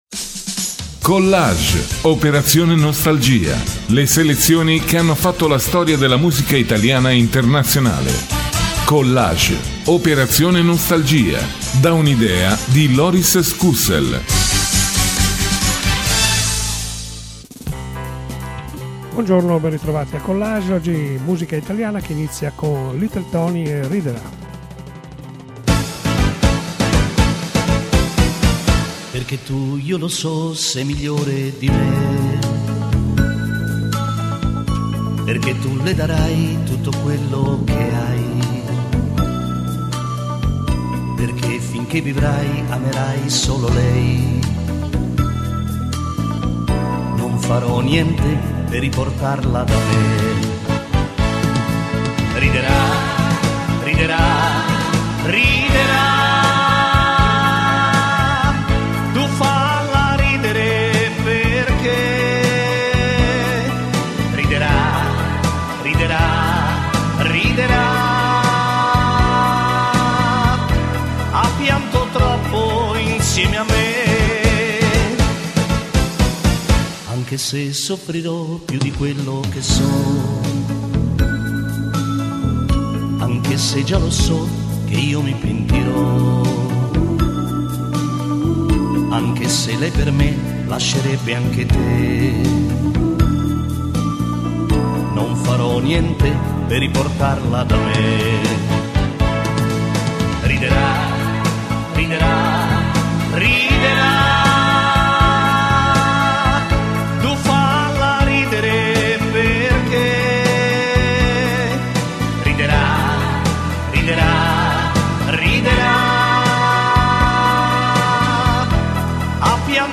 GIORNALE RADIOPIU 19 GENNAIO 2022